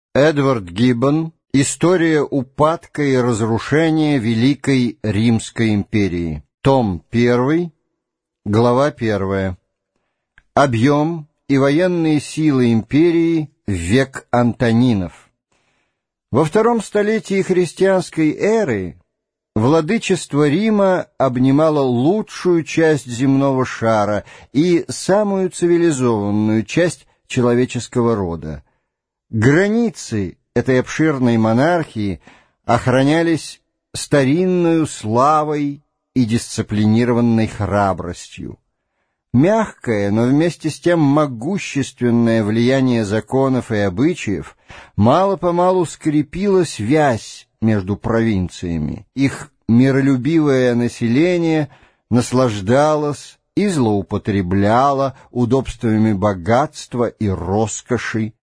Аудиокнига История упадка и разрушения Римской Империи. Том 1 | Библиотека аудиокниг